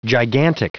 Prononciation du mot gigantic en anglais (fichier audio)
Prononciation du mot : gigantic